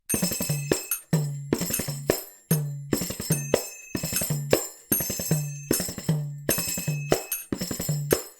Kanjira  (Inde du sud)
C’est le plus petit tambour sur cadre au monde – il mesure environ 18 à 20 cm de diamètre - mais c'est aussi l'un des instruments de percussion les plus complexes, permettant une grande virtuosité.
Les basses sont riches et rondes, les aigus pleins de slaps staccato précis. Les kanjiras sont munis d’une petite clochette ou cymbalette.
kanjira.mp3